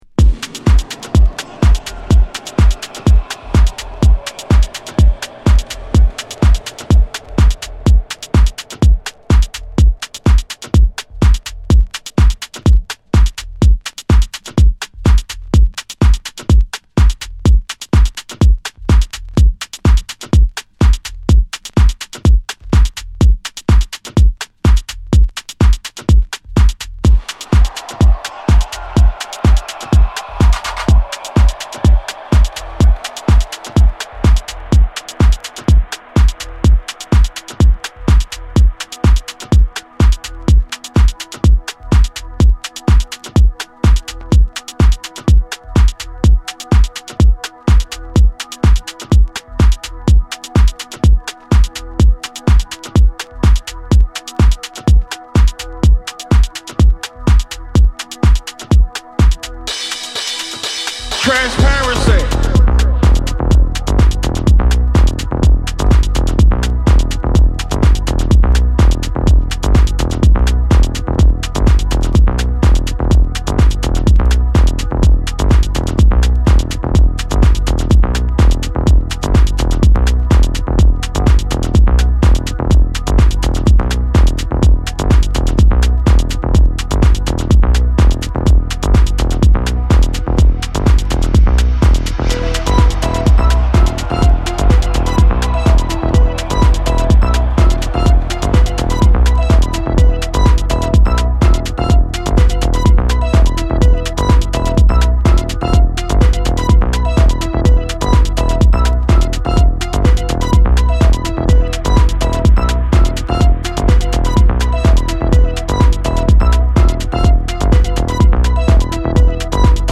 テクノハウス